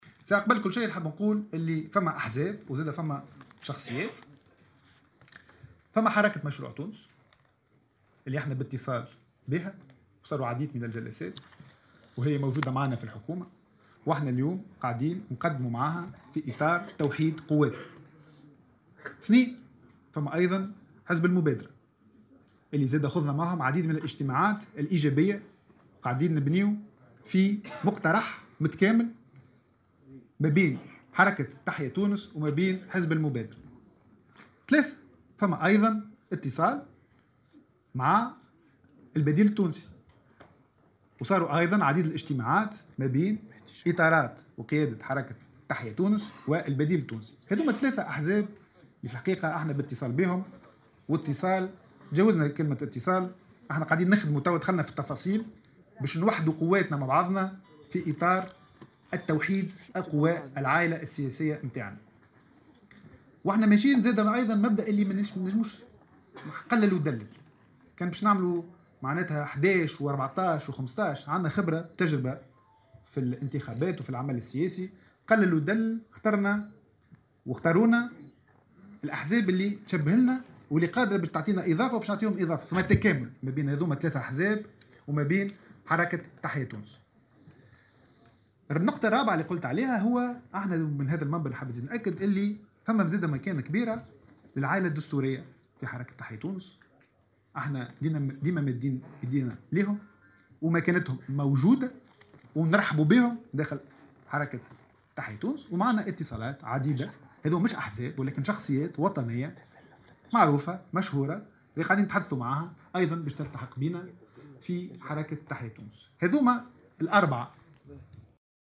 وأضاف في تصريح اليوم لمراسل "الجوهرة أف أم" على هامش ندوة صحفية عقدها الحزب، أنه تم الاتصال بكل من أحزاب "حركة مشروع تونس" و"المبادرة" و حزب "البديل التونسي".كما أكد أيضا أن الحزب يبقى مفتوحا أمام العائلة الدستورية، ورموزها الذين سيظلون مرحب بهم، مشيرا إلى أن عديد الشخصيات الوطنية المعروفة أعربت عن رغبتها في الالتحاق بالحركة.